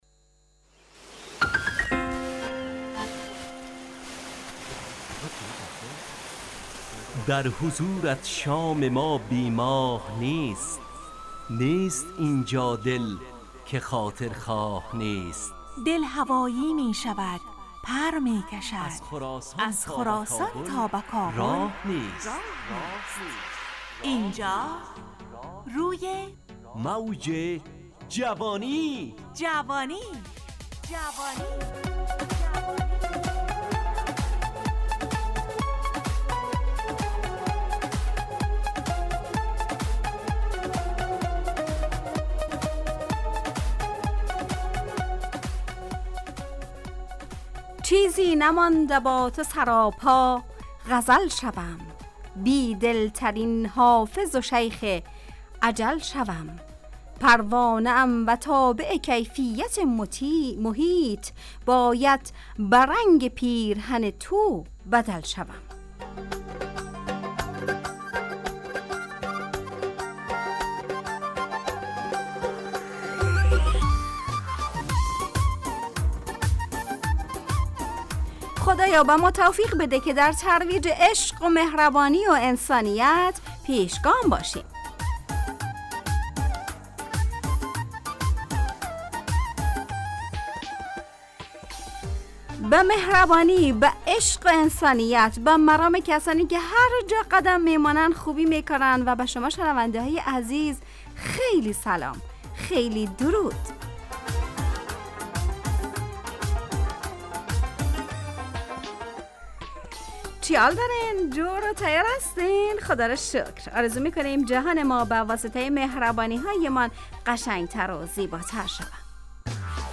روی موج جوانی، برنامه شادو عصرانه رادیودری.
همراه با ترانه و موسیقی مدت برنامه 70 دقیقه . بحث محوری این هفته (انسانیت) تهیه کننده